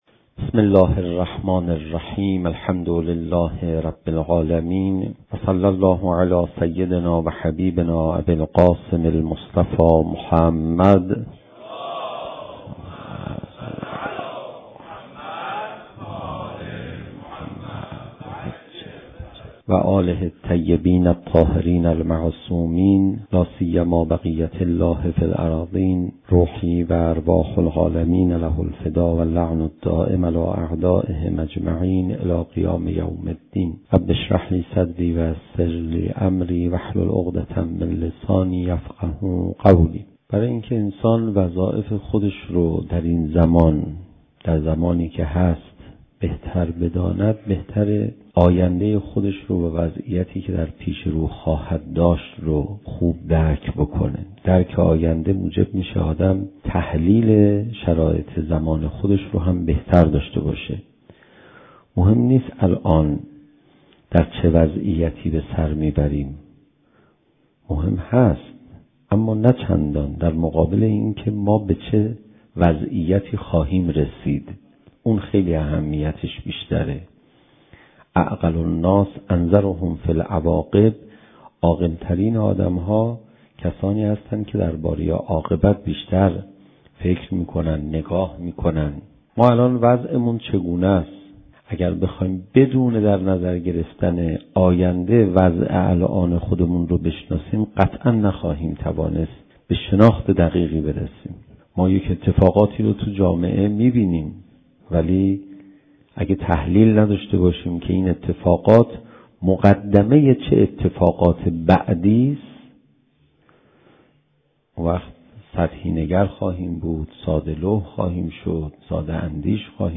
زمان: 44:33 | حجم: 10.5 MB | تاریخ: 1395 | مکان: حسینیة آیت الله حق شناس